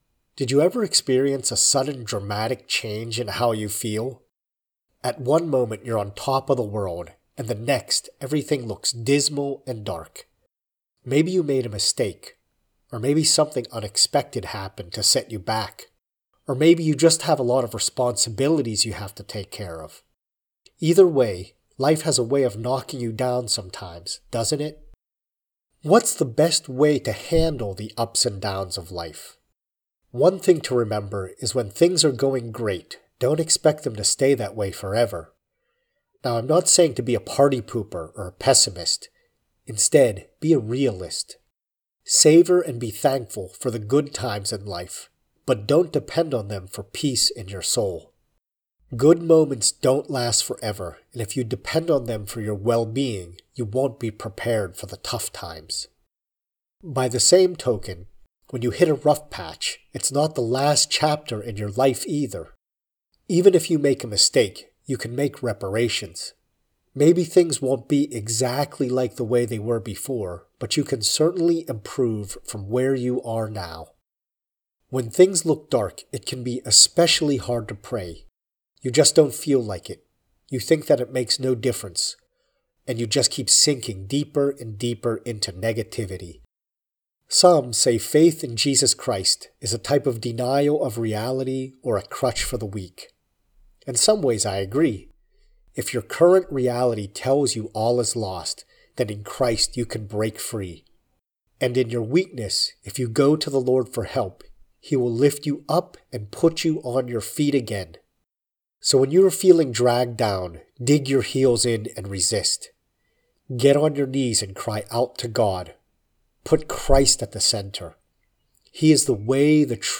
A message and prayer